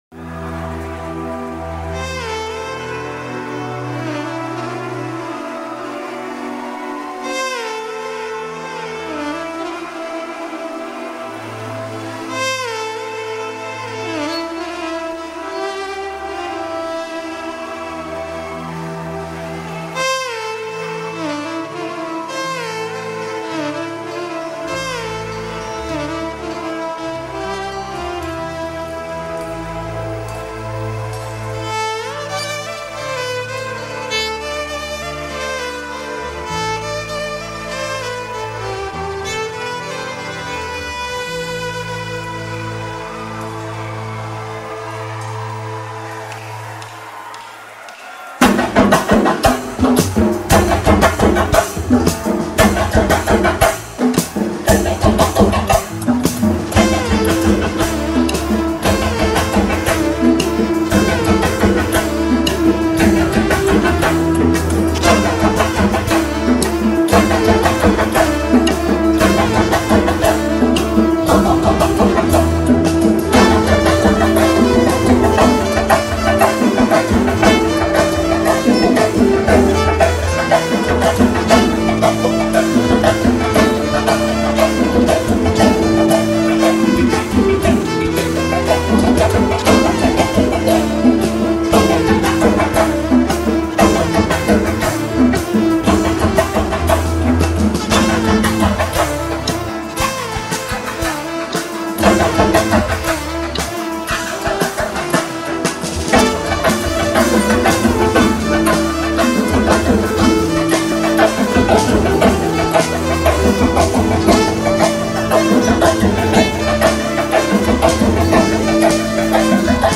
Instrumental Music And Rhythm Track